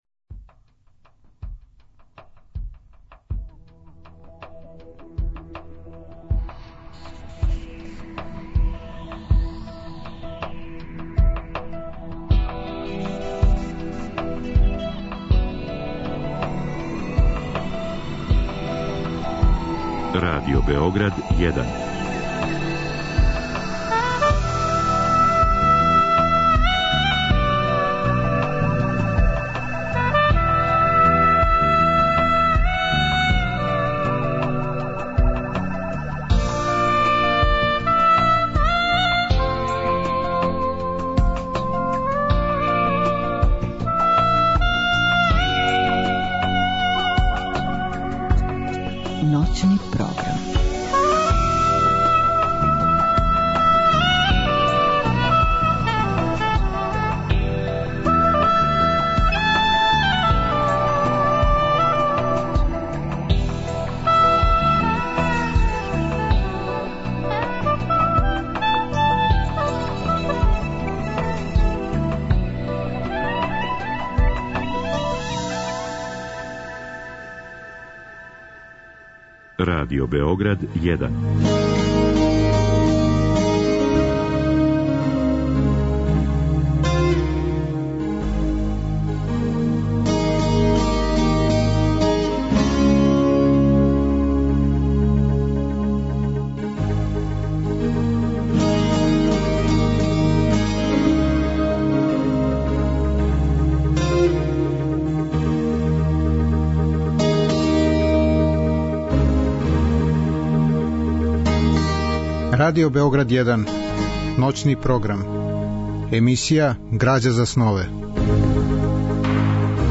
Разговор и добра музика требало би да кроз ову емисију и сами постану грађа за снове.